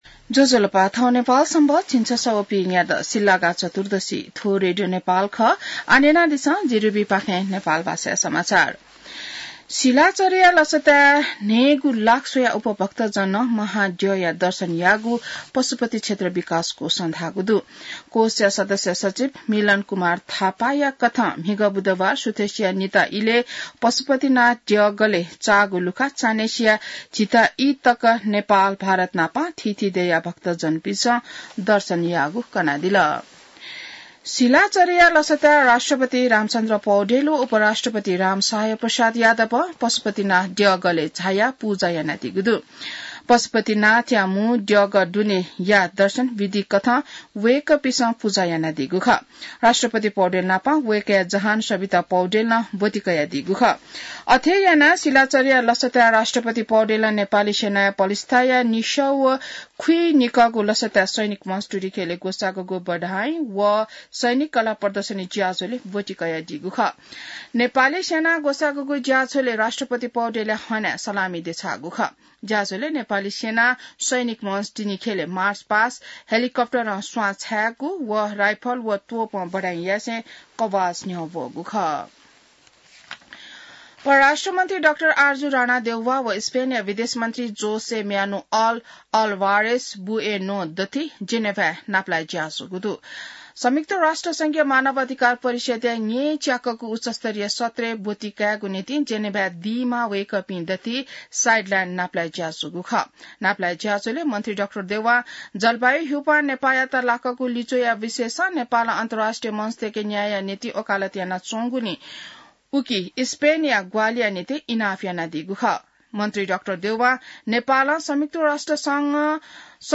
नेपाल भाषामा समाचार : १६ फागुन , २०८१